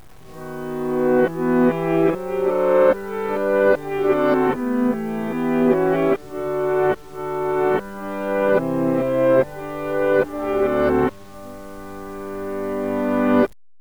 Now the tape of the last recording is played backwards so that the chorale is heard forward again, but with an interesting difference